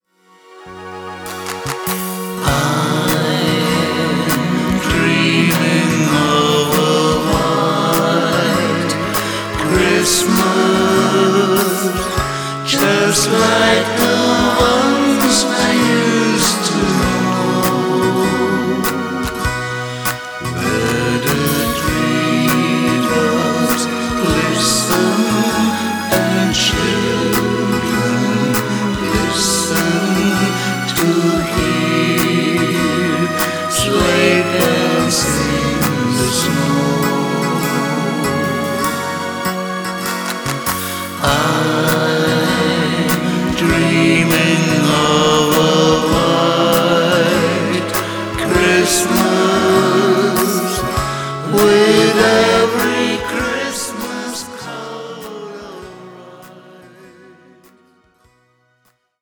CHRISTMAS